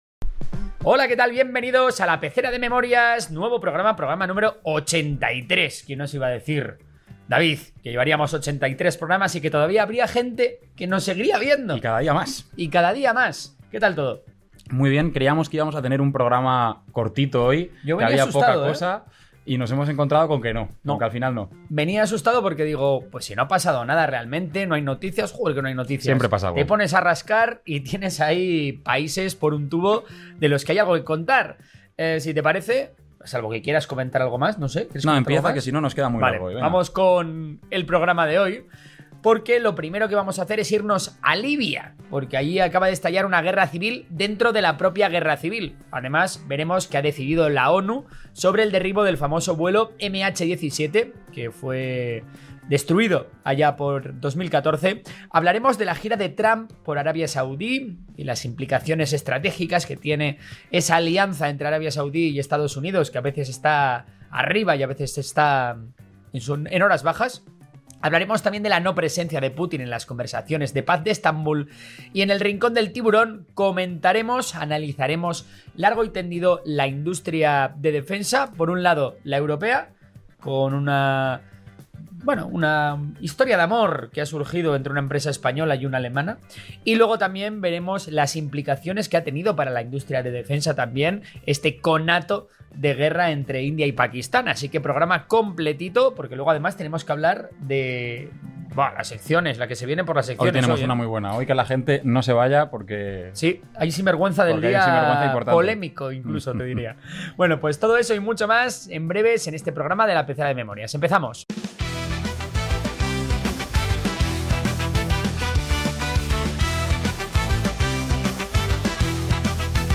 Relato de guerra